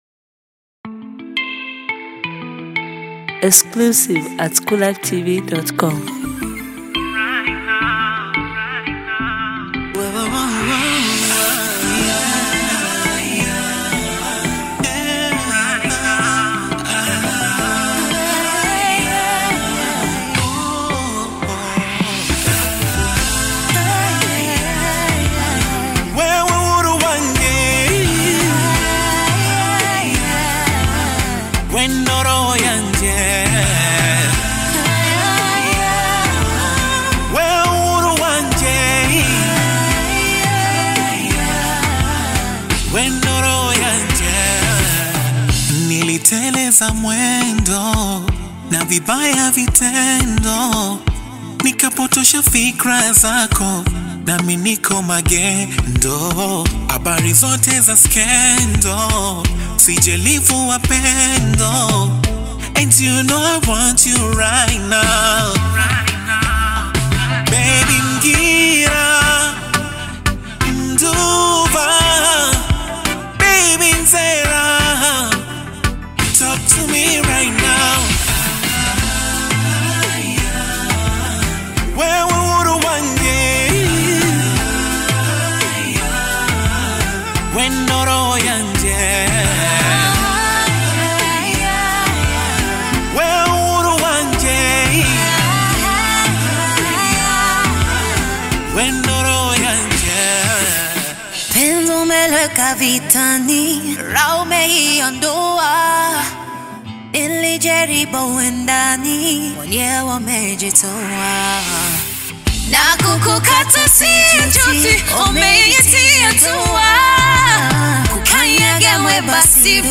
sensational ballad